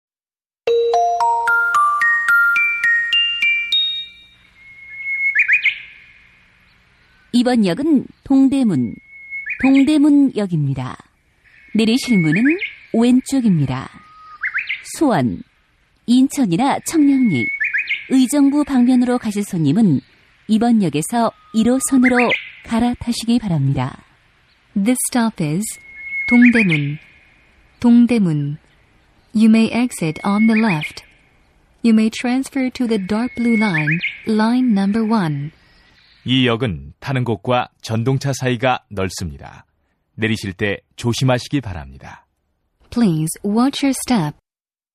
05seoul_subway.mp3